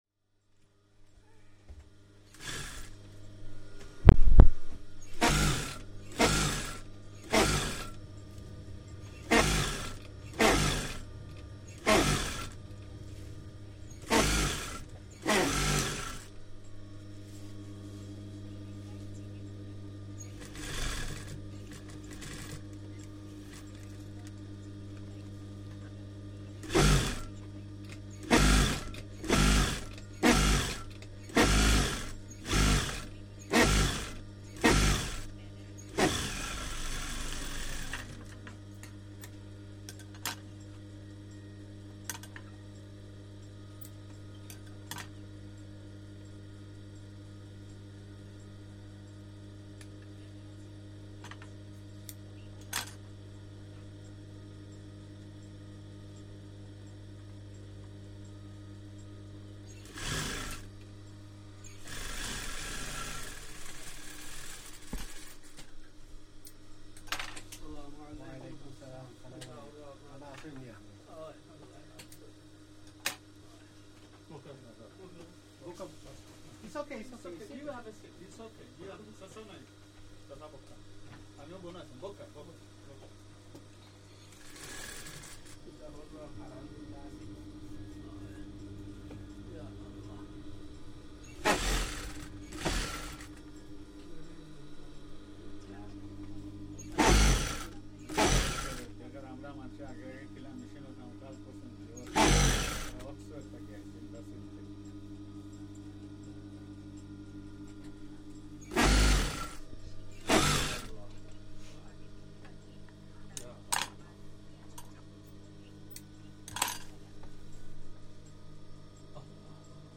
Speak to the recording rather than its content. Underground with a Bangladeshi tailor Part of the Migration Sounds project, the world’s first collection of the sounds of human migration.